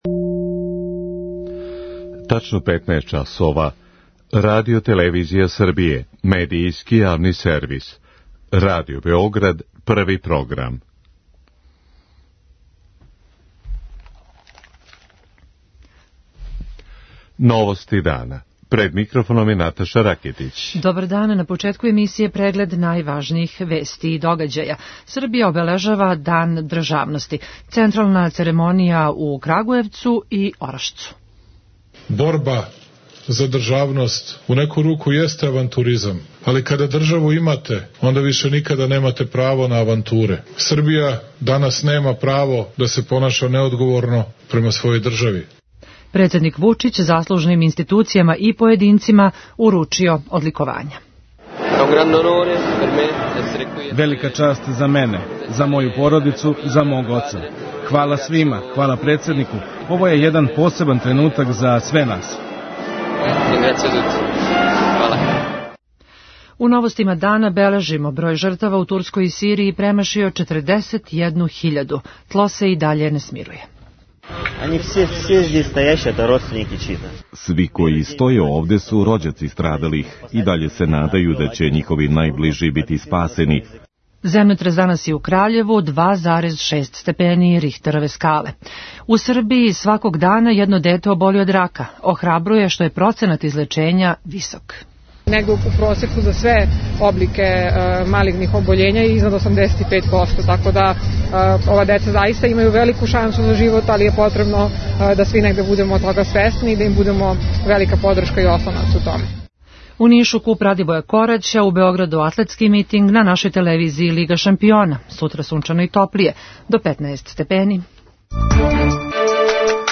Поводом Дана државности, уручено је 71 одликовање, а добило га је 169 лица и институција. преузми : 6.49 MB Новости дана Autor: Радио Београд 1 “Новости дана”, централна информативна емисија Првог програма Радио Београда емитује се од јесени 1958. године.